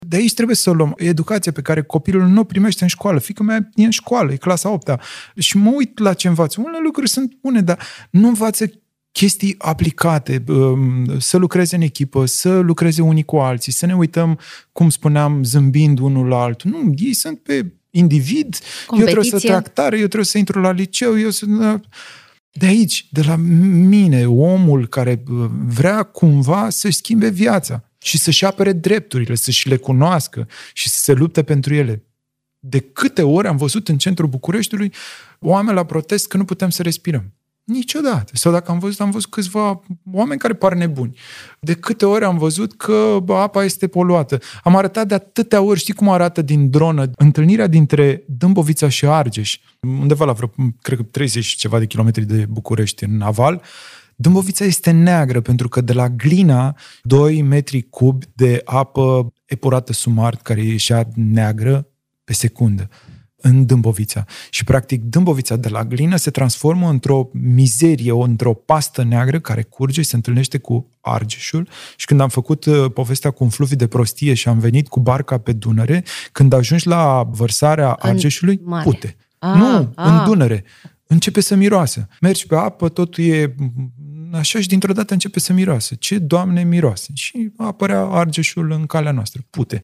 Invitat la Misiunea Verde